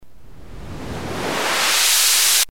ノイズに限っては、このOSC部分にすでにフィルターが付いてるのだ。
= LPF　 = BPF